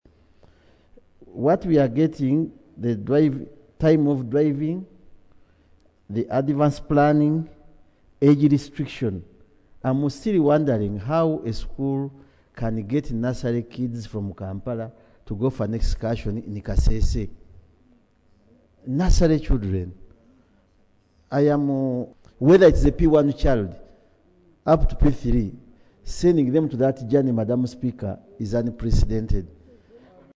Raising the matter of national importance on Tuesday, 05 August 2025, Kalungu West County Member of Parliament, Hon. Joseph Ssewungu questioned the Ministry of Education’s oversight in authorising school excursions involving children.
Ssewungu on school trips .mp3